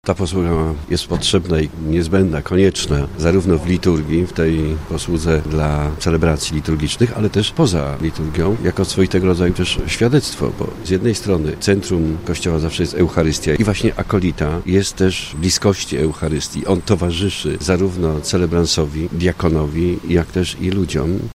Biskup diecezji zielonogórsko-gorzowskiej, Tadeusz Lityński, mówi, że posługa lektorów i akolitów jest bardzo ważna podczas eucharystii.